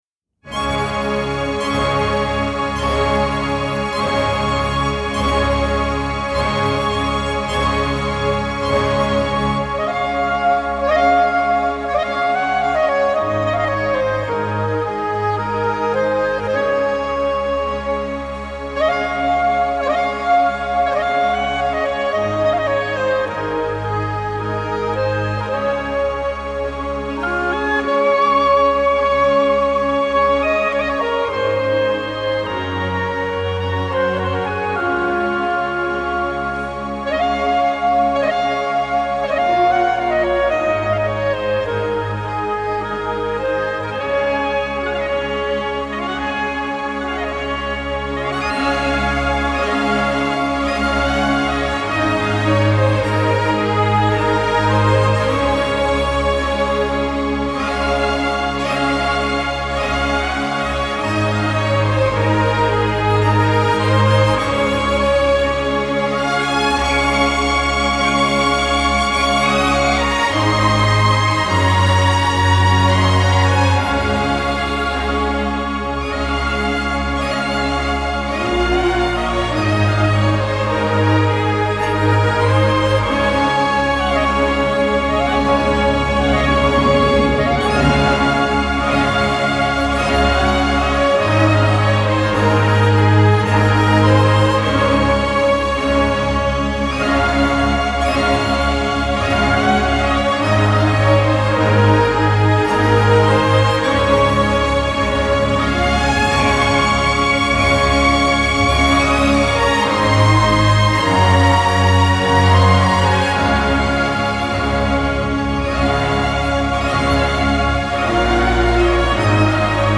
Genre: Pop, Instrumental, Easy Listening